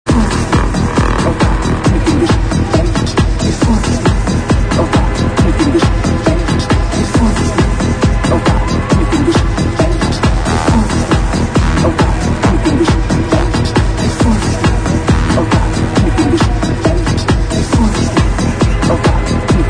pls help id this:curius but nice trance track
in this track a german girl is telling us sth. dont understand it but nice..who knows this song
I had this track a few years back but lost it in a crash. its definitely by a psychedelic artist i just can't remember which. i hope that helped a little.